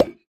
Minecraft Version Minecraft Version 1.21.5 Latest Release | Latest Snapshot 1.21.5 / assets / minecraft / sounds / block / decorated_pot / insert2.ogg Compare With Compare With Latest Release | Latest Snapshot